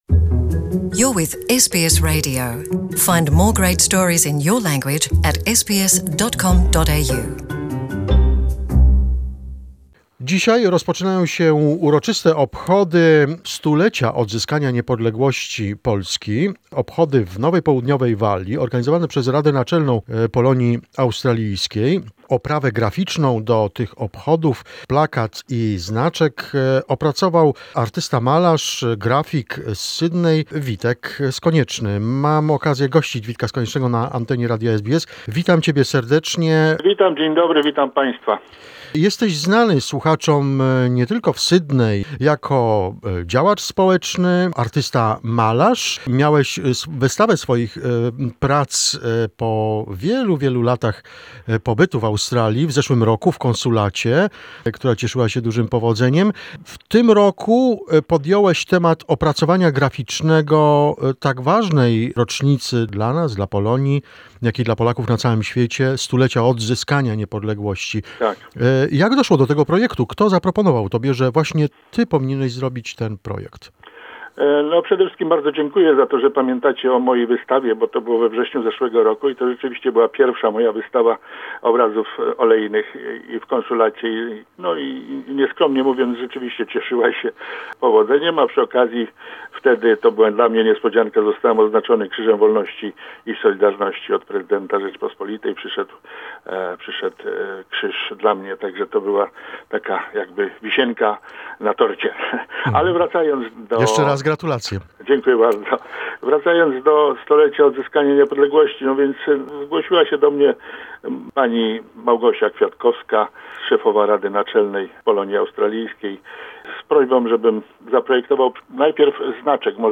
Interview with a graphic artist